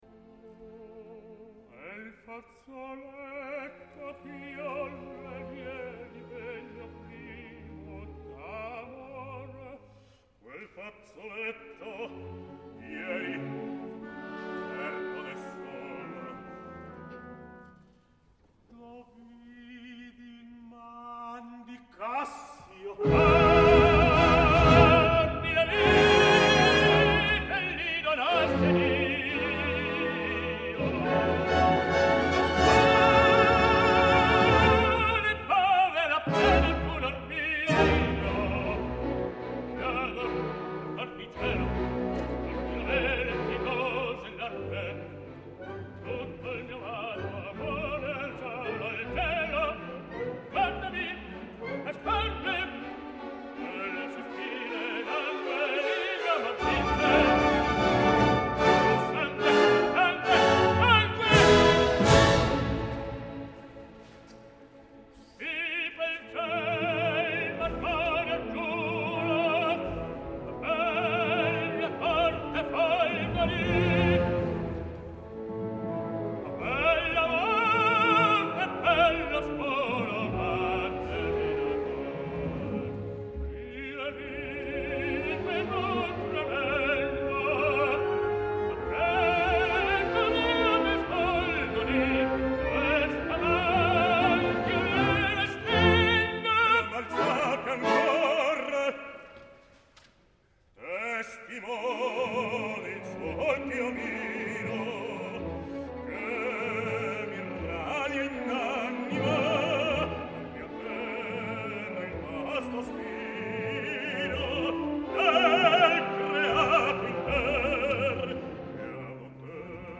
: I brani qui presentati sono tutti tratti da  registrazioni amatoriali , spesso realizzate da amici o colleghi Ne  è vietata la divulgazione con qualsiasi mezzo o utilizzo a  scopo commerciale.
Here below you can find some arias performed by  tenor  Antonello  Palombi.